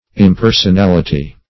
Search Result for " impersonality" : The Collaborative International Dictionary of English v.0.48: Impersonality \Im*per`son*al"i*ty\, n. The quality of being impersonal; want or absence of personality.